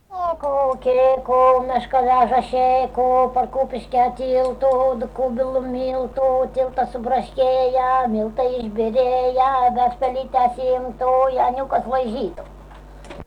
smulkieji žanrai
Atlikimo pubūdis vokalinis